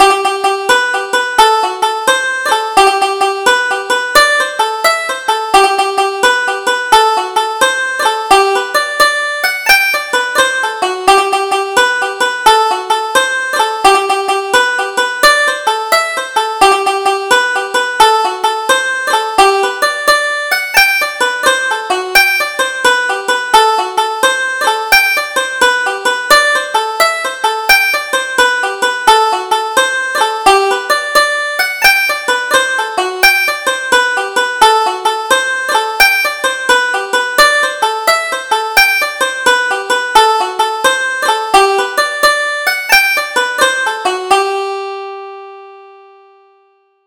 Double Jig: Jackson's Rolling Jig